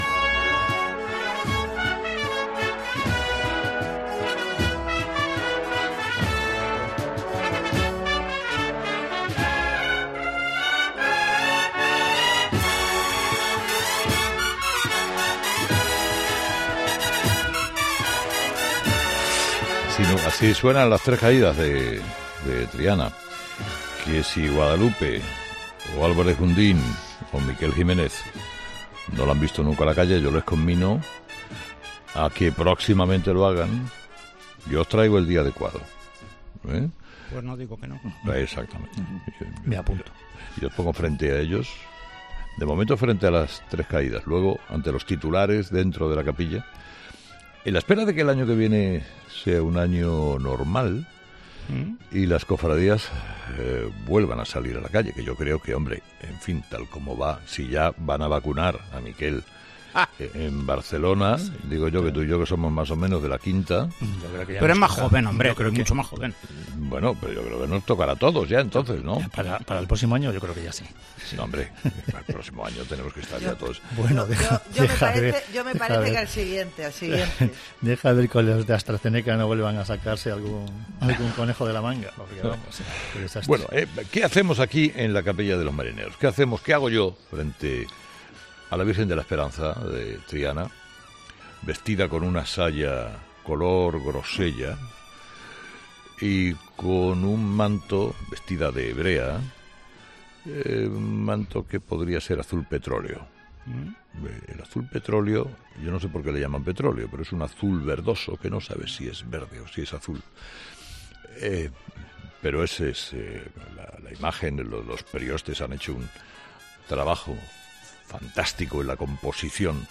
Este viernes de Dolores, Carlos Herrera se encuentra en la Capilla de los Marineros de la Esperanza de Triana
Este viernes, viernes de Dolores, Carlos Herrera se encuentra a los pies de la Virgen de la Esperanza de Triana, en la Capilla de los Marineros.